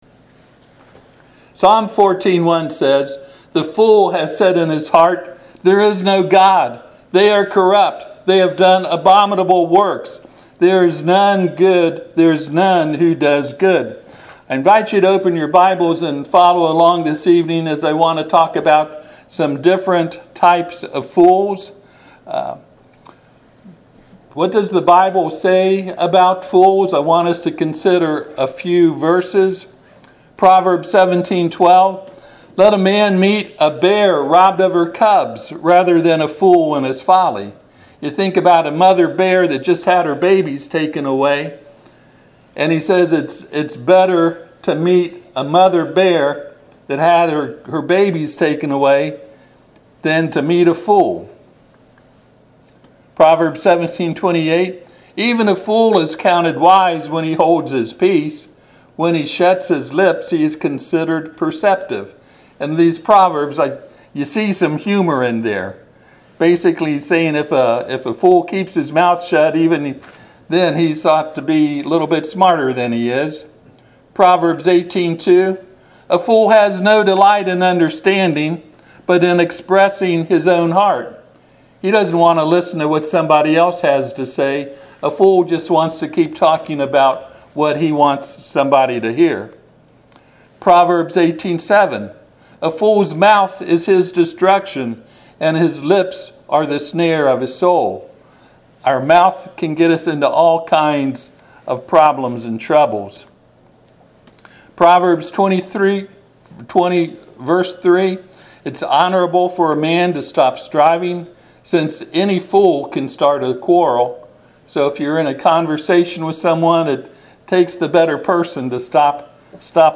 Psalm 14:1 Service Type: Sunday PM https